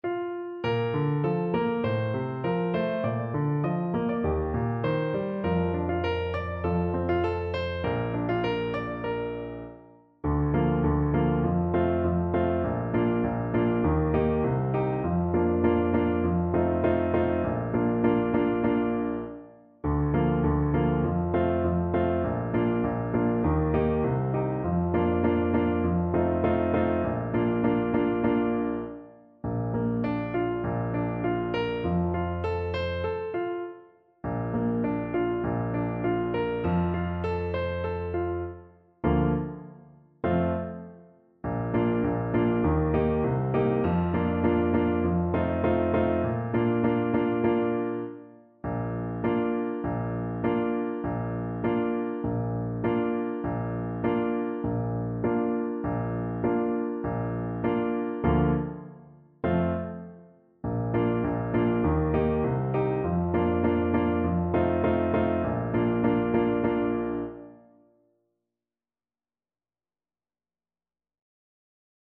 French Horn version
4/4 (View more 4/4 Music)
Moderato